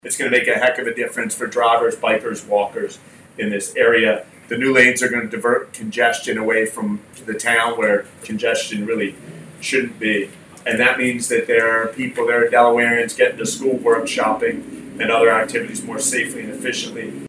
With this long-awaited project at the point of completion this week, safety will be improved and congestion reduced in the Millsboro area, as Governor Matt Meyer assured Delawareans during the news conference that took place here at the intersection of Hollyville Road and Route 24…